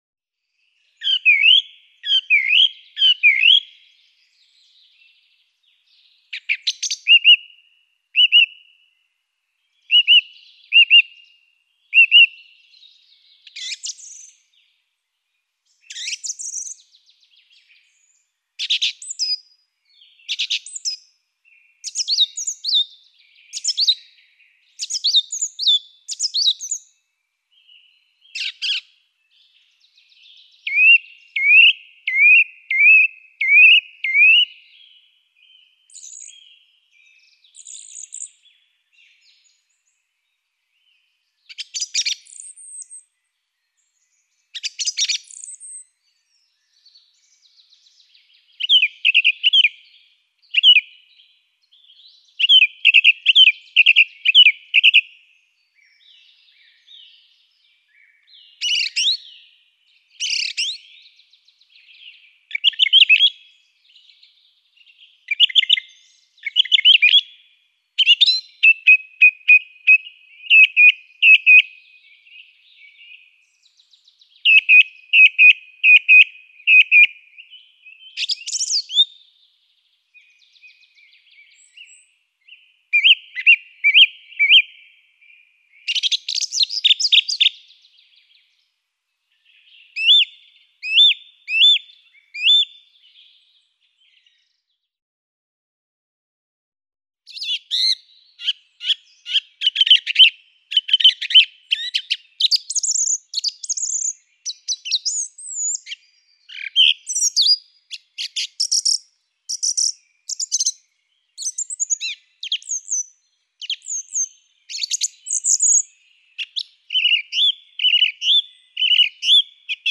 Turdus_philomelos_Track_65.mp3